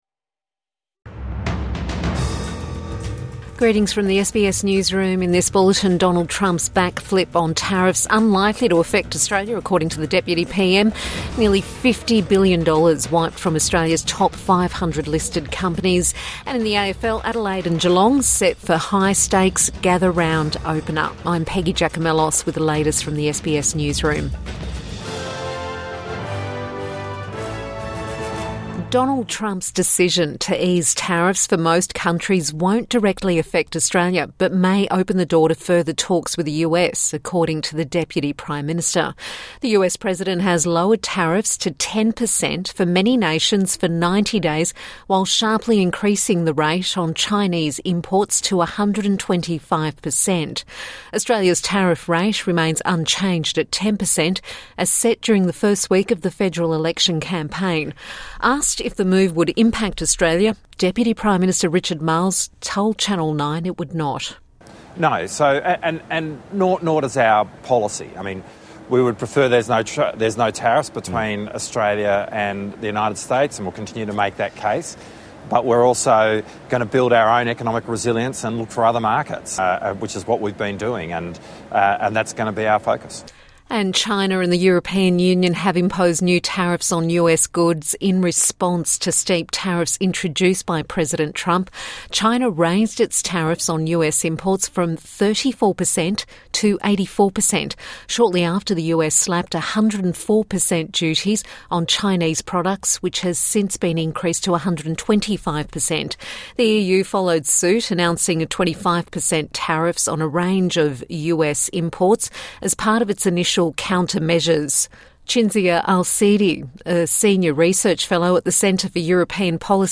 Donald Trump's backflip on tariffs unlikely to affect Australia | Midday News Bulletin 10 April 2025